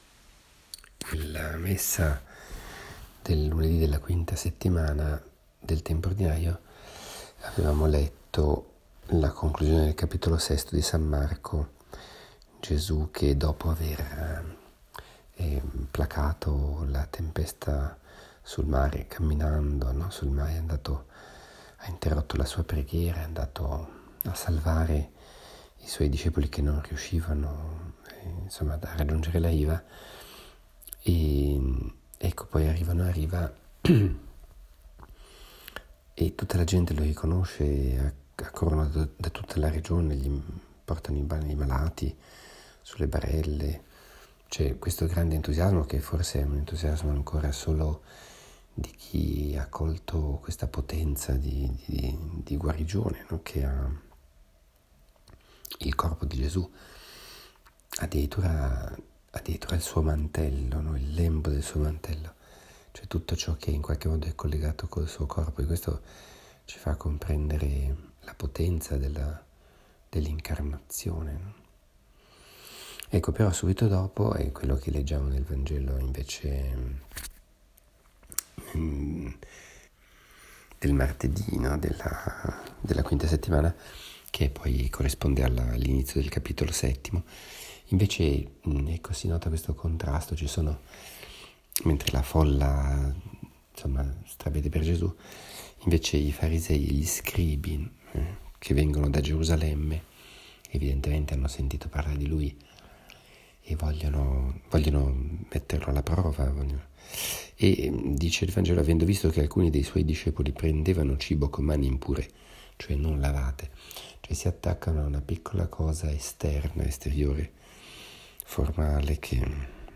Commento al vangelo (Mc 7,1-13) del 6 febbraio 2018, martedì della V settimana del Tempo Ordinario.